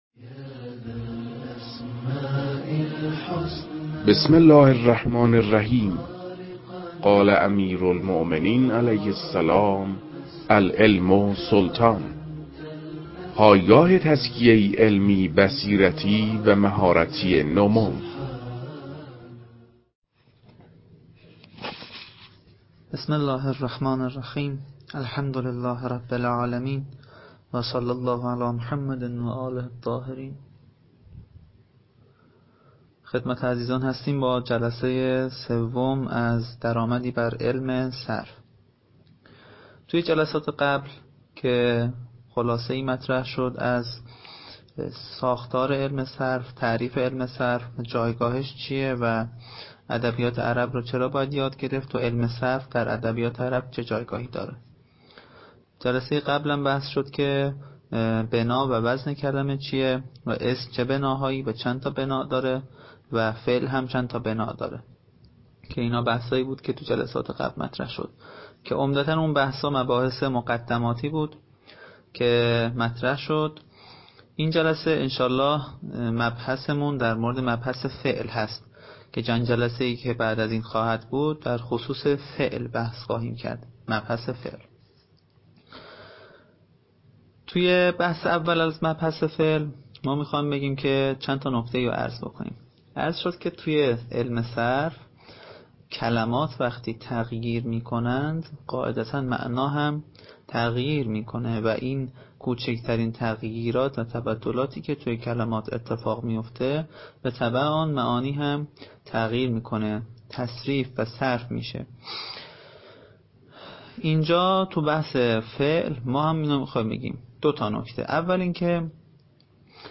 در این بخش، کتاب «درآمدی بر صرف» که اولین کتاب در مرحلۀ آشنایی با علم صرف است، به صورت ترتیب مباحث کتاب، تدریس می‌شود.
در تدریس این کتاب- با توجه به سطح آشنایی کتاب- سعی شده است، مطالب به صورت روان و در حد آشنایی ارائه شود.